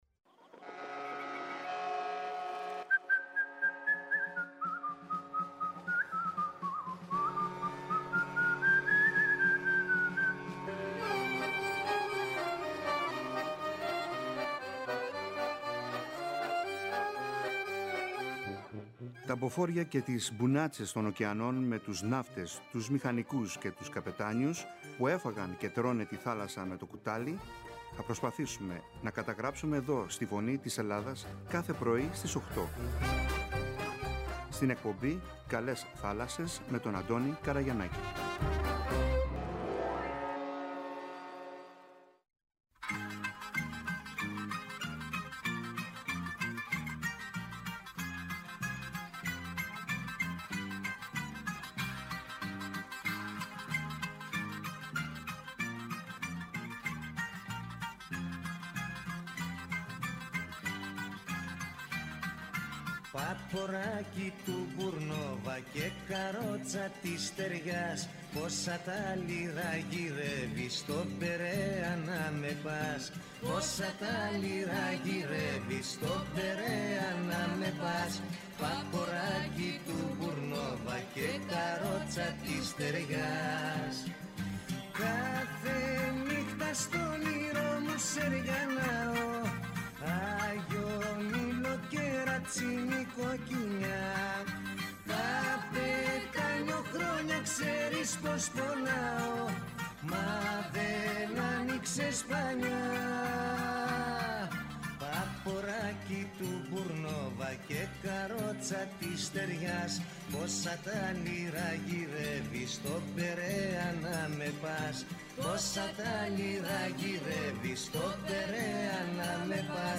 Επίσης από τον Πειραιά μίλησε ο αντιδήμαρχος Προγραμματισμού και Βιώσιμής Ανάπτυξης Δημήτρης Καρύδης για τις εκδηλώσεις «Ημέρες Θάλασσας» και «Ποσειδώνια 2024».